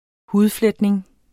Udtale [ ˈhuðˌflεdneŋ ]